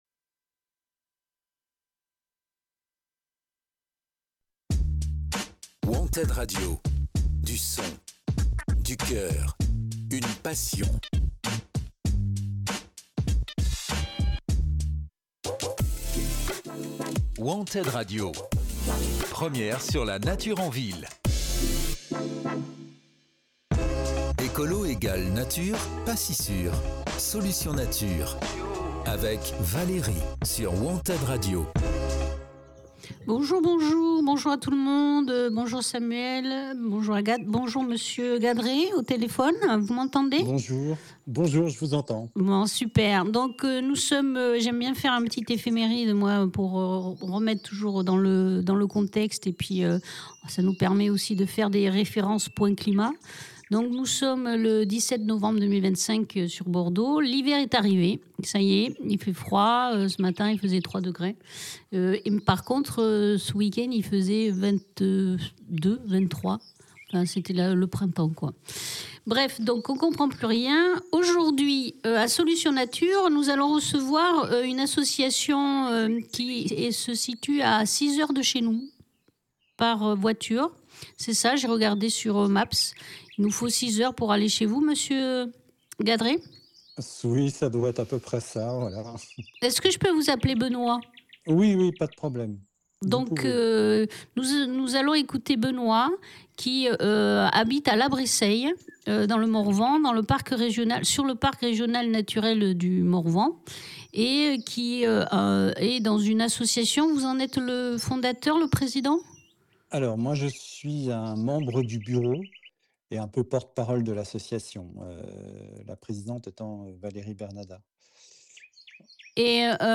Quand les coupes rases détruisent nos paysages et nos climats. Visite guidée de la forêt du Morvan